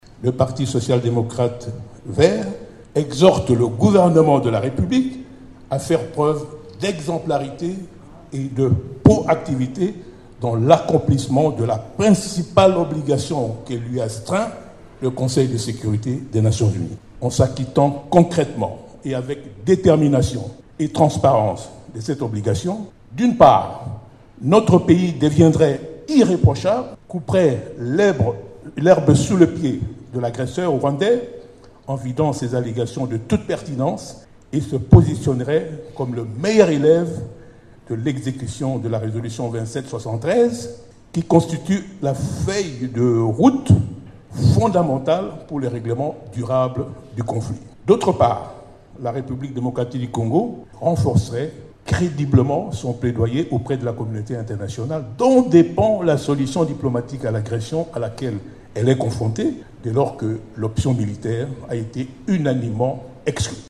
Le président national de ce parti, membre de l’Union sacrée, Léonard She Okitundu a livré son point de vue vendredi 23 janvier, lors de sa rentrée politique à Kinshasa.
Les propos de Léonard She Okitundu :